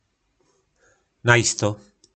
Slovník nářečí Po našimu
Určitě - Najisto